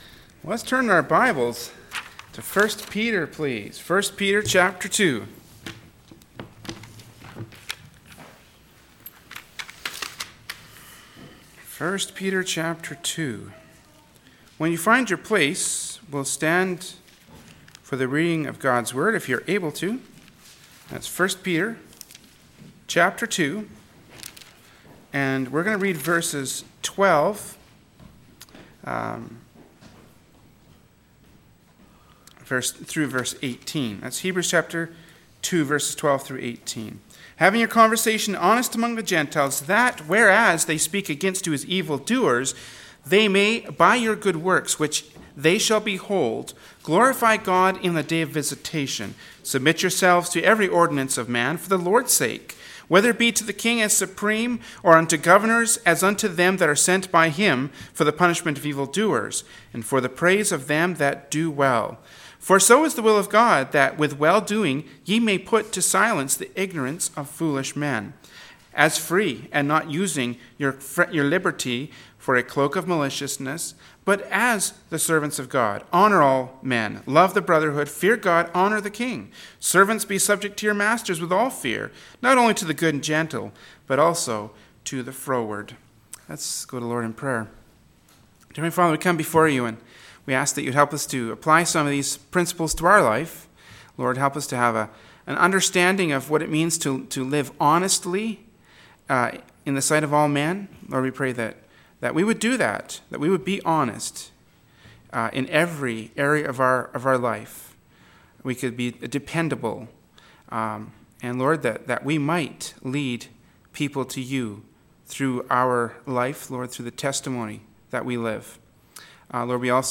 “1st Peter 2:12-18” from Wednesday Evening Service by Berean Baptist Church.